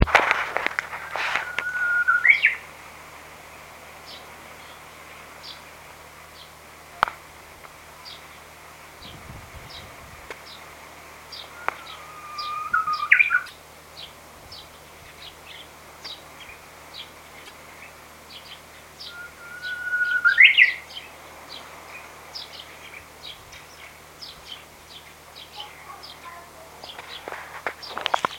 japanese_bush_warbler.MP3